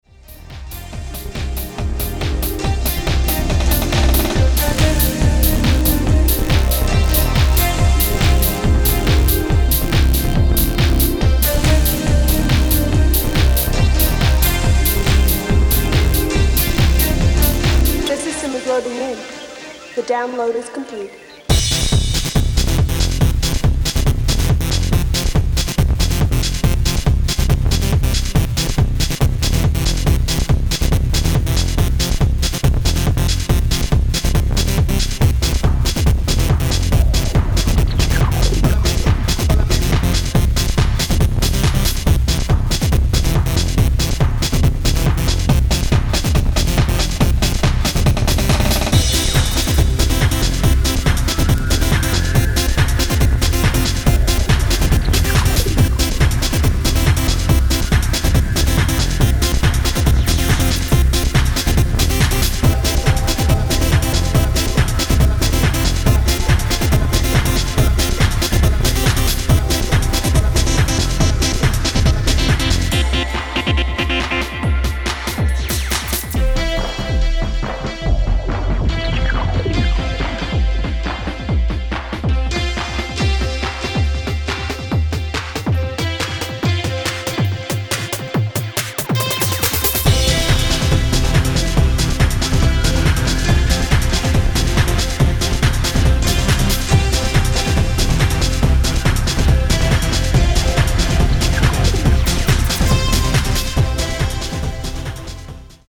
dance/electronic
uplifting
House
Techno
Club Anthems